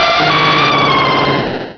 Cri de Lugia dans Pokémon Rubis et Saphir.